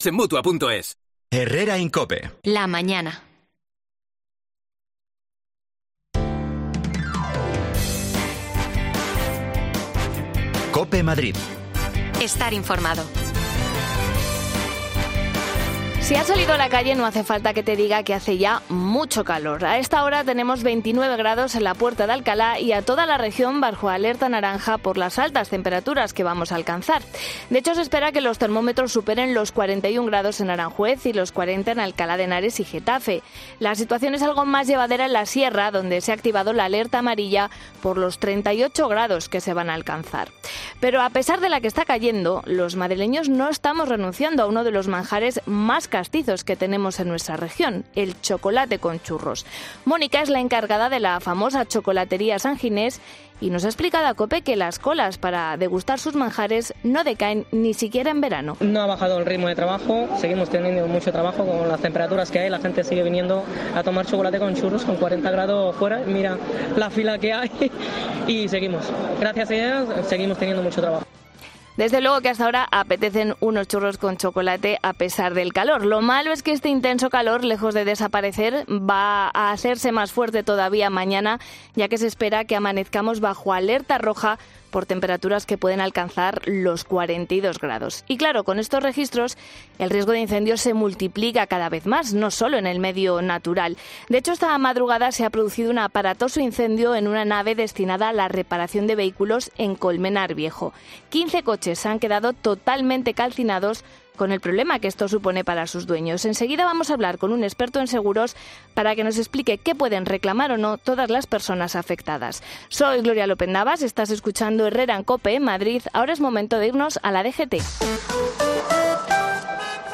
Hablamos con un experto que nos explica qué podemos reclamar